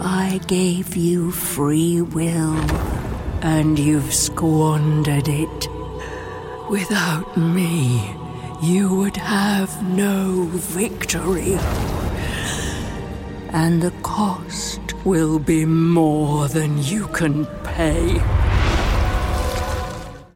British ● Heightened RP ● RP Adult ● Senior
Videogame